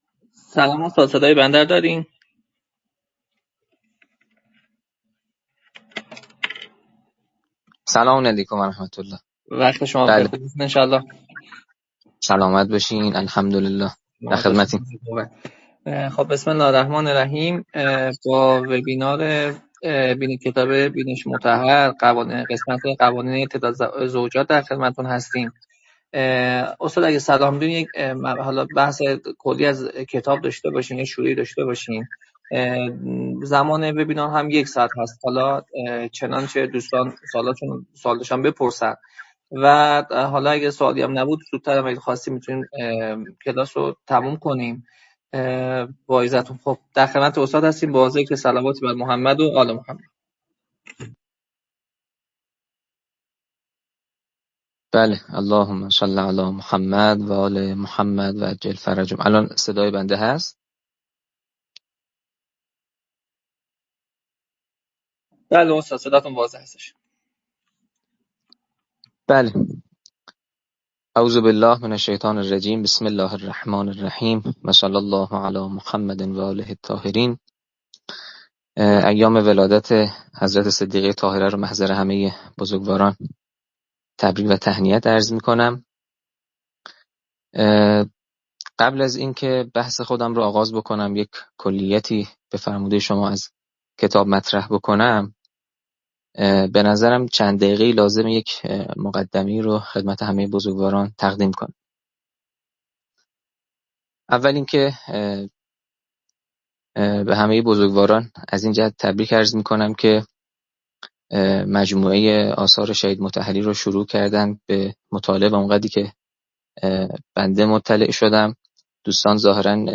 حقوق زن و مرد از نگاه اسلام - قوانین {ازدواج موقت، تعدد زوجات و ...} (بینش مطهر) - جلسه-پرسش-و-پاسخ